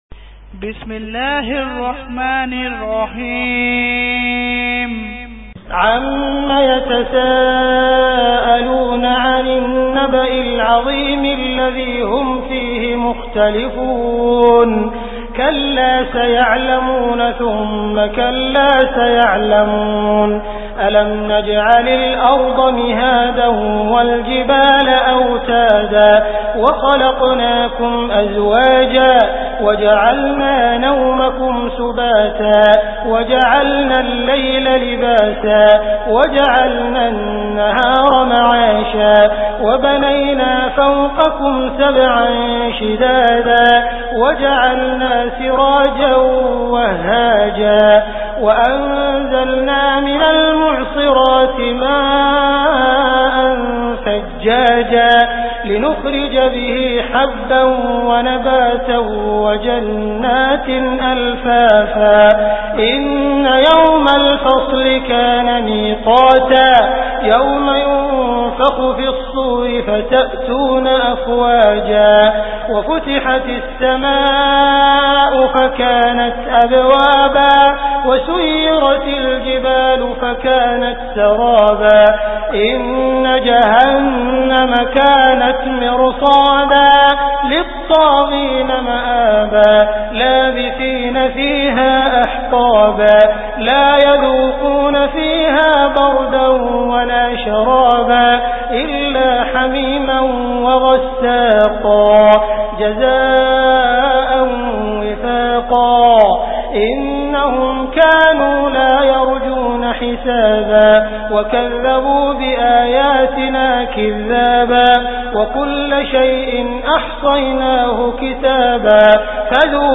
Surah An Naba Beautiful Recitation MP3 Download By Abdul Rahman Al Sudais in best audio quality.